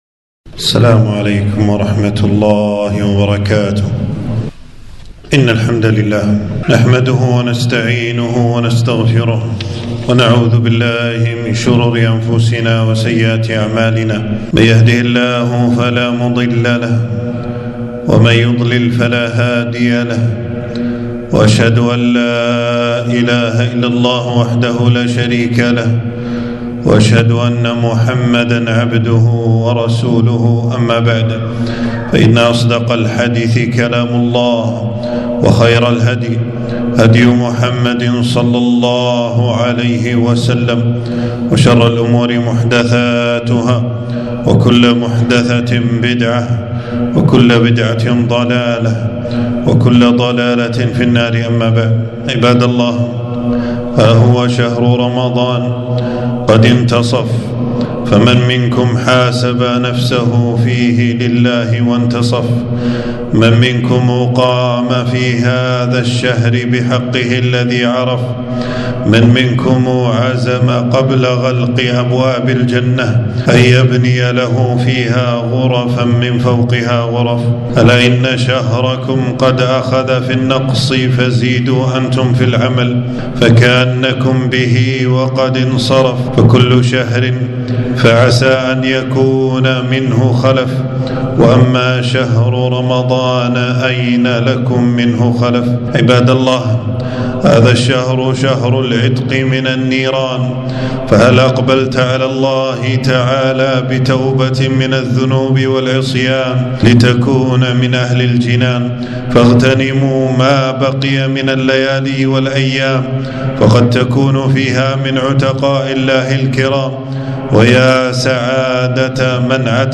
خطبة في يوم 14 رمضان 1446هـ في مسجد السعيدي بالجهرا.